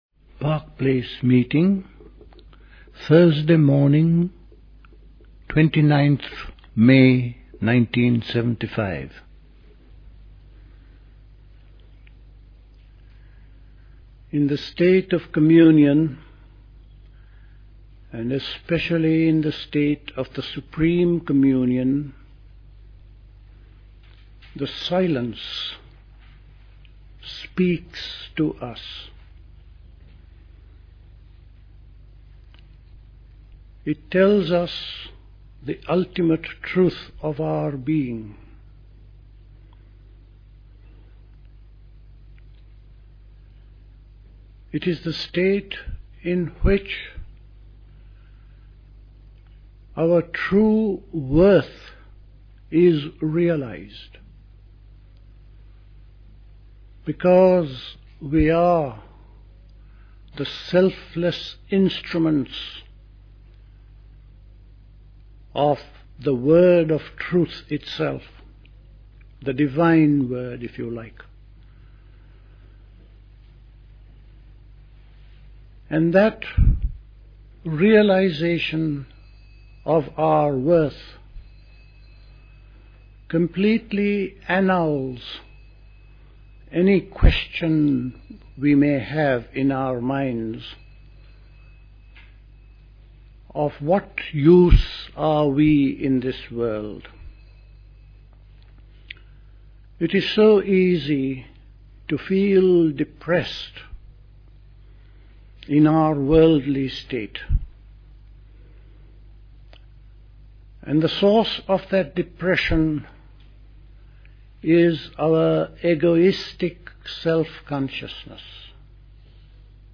Recorded at the 1975 Park Place Summer School.